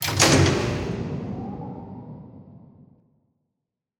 HeavySwitch.wav